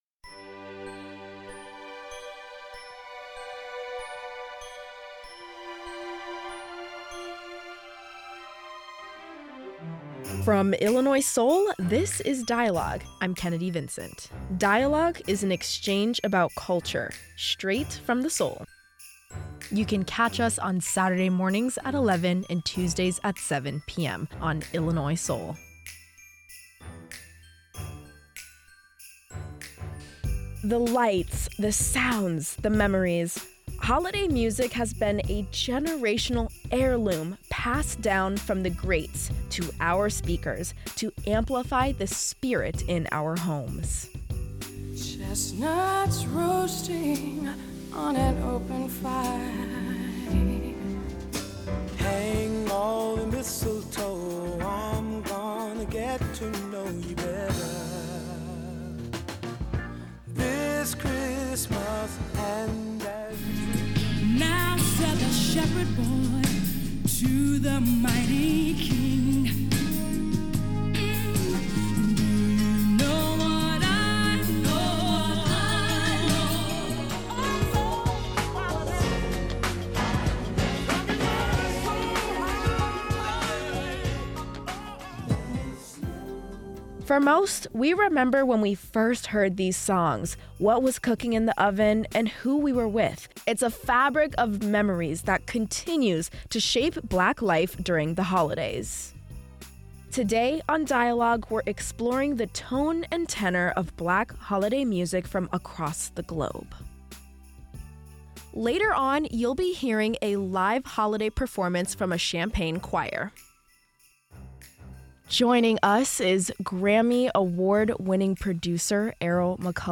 Bethel AME Church Choir